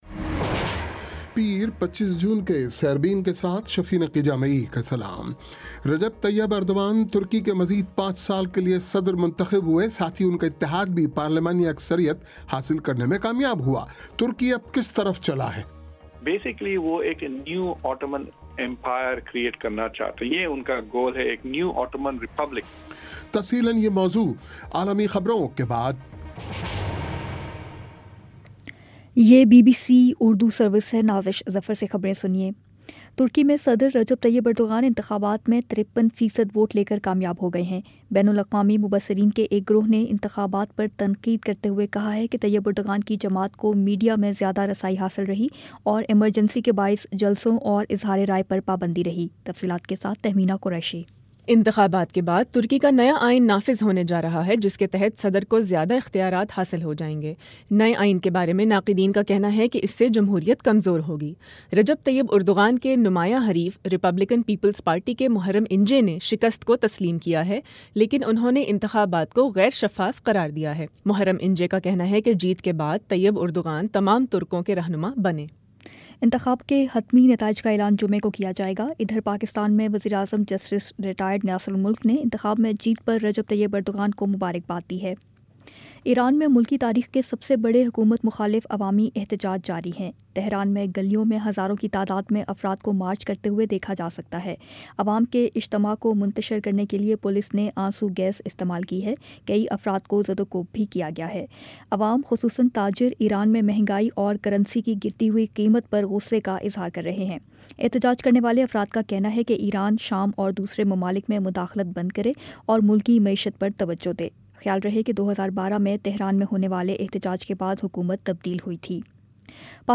پیر 25 جون کا سیربین ریڈیو پروگرام
بی بی سی اردو کا فلیگ شپ ریڈیو پروگرام روزانہ پاکستانی وقت کے مطابق رات آٹھ بجے پیش کیا جاتا ہے جسے آپ ہماری ویب سائٹ، اپنے موبائل فون، ڈیسک ٹاپ، ٹیبلٹ، لیپ ٹاپ اور اب فیس بُک پر سن سکتے ہیں